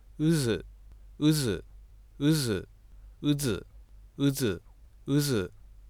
以下のサンプルファイルは「ウズ」を何回か発音してみたもので，摩擦音で発音したものもあれば破擦音で発音したものもあります。
左が有声摩擦音で，右が有声破擦音です。